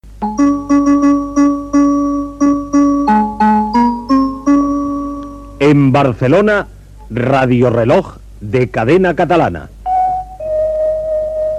Indicatiu de l'emissora versionant les notes del tema